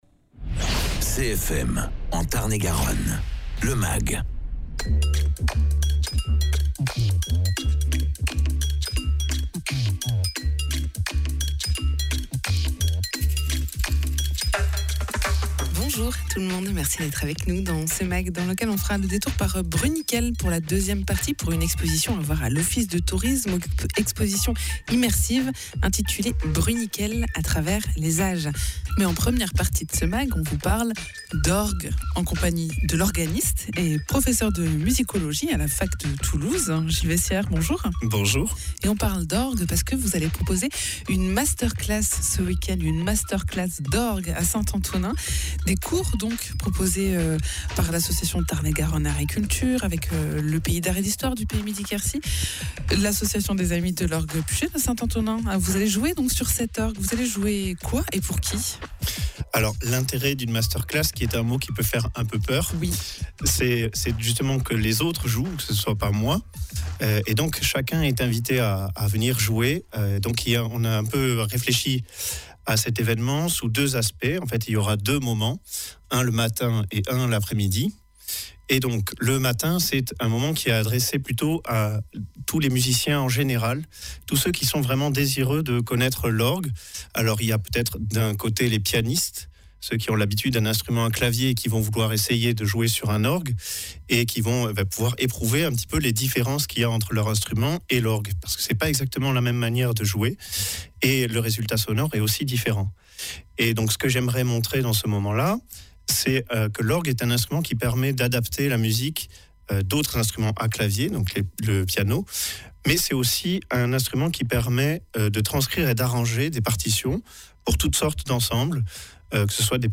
organiste